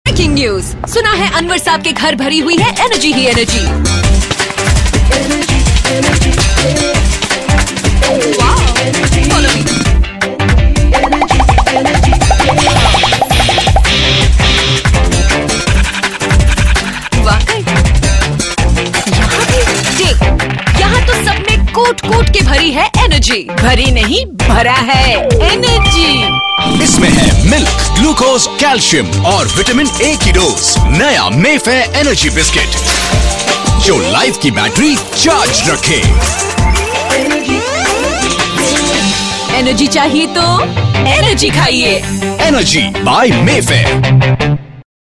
File Type : Tv confectionery ads